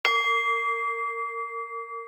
cuckoo-clock-01.wav